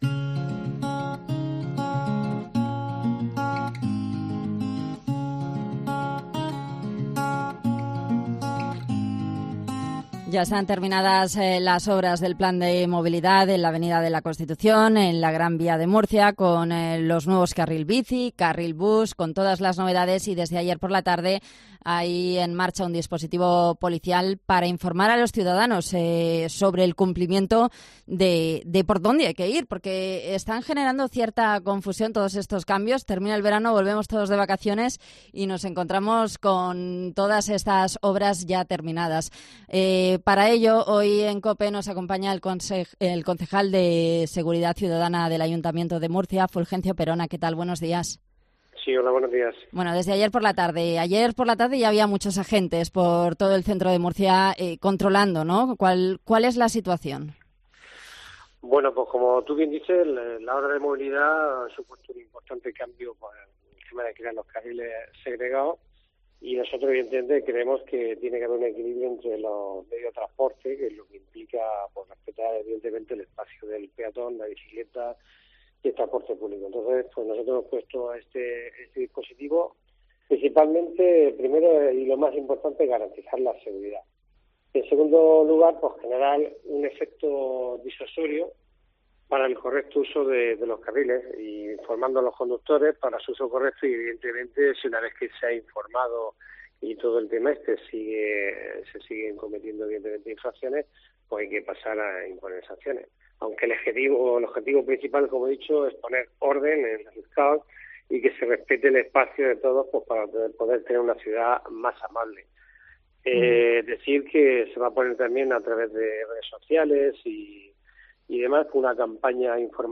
ENTREVISTA
Fulgencio Perona, concejal de Seguridad Ciudadana